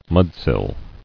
[mud·sill]